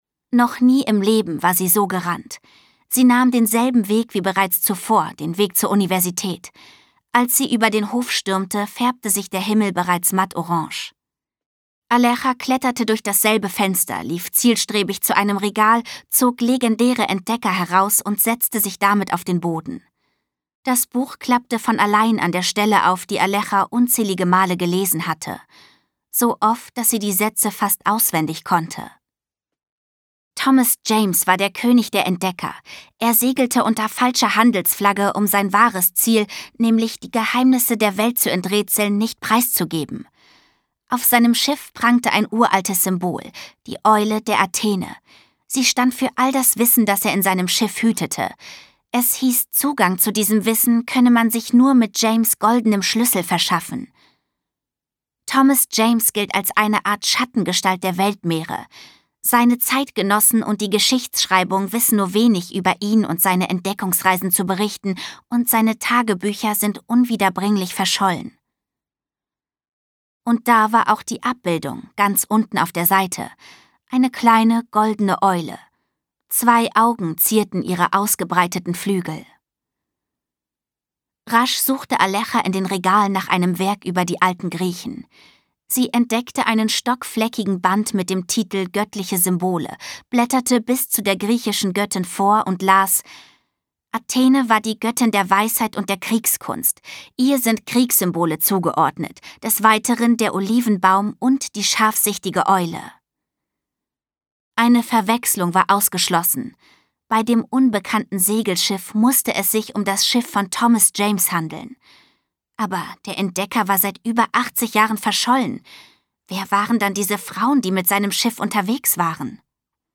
Schlagworte Abenteuer auf hoher See • Abenteuergeschichte • Abenteuergeschichte für Mädchen • Abenteuerhörbuch • Aleja • Auf hoher See • Geschenk für Mädchen • Hörbuch für Kinder • Hörbuch für Mädchen • Mädchenhörbuch • Piraten • Piratengeschichte • Piratenschiff • Piratinnen • Schatzsuche • Schulalter • Sevilla • spannendes Abenteuer